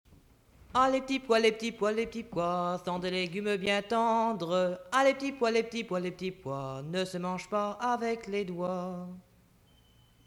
Chanson